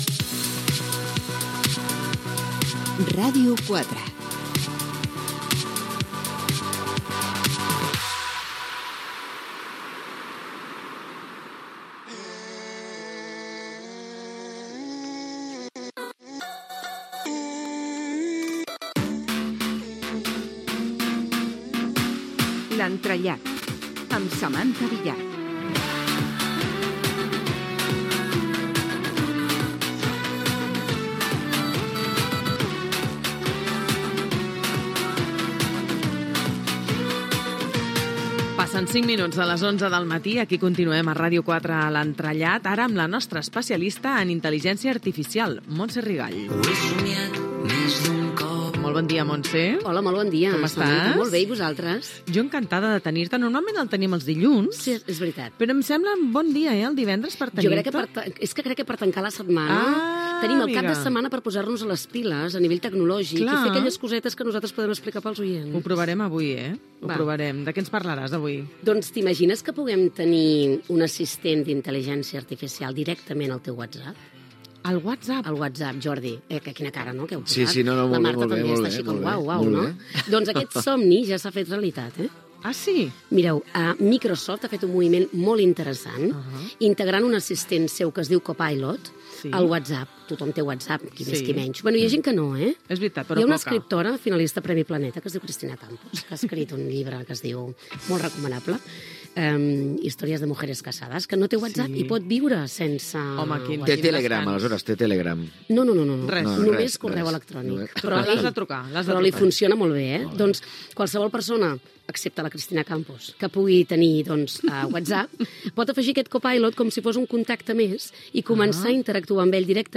c67bc22f281b41aec05d1d480032af593c2a3b49.mp3 Títol Ràdio 4 Emissora Ràdio 4 Cadena RNE Titularitat Pública estatal Nom programa L'entrellat Descripció Indicatiu de la ràdio, careta del programa, Microsoft ha integrant el seu assistent d'intel·ligència artificial Copilot a WhatsApp.
Info-entreteniment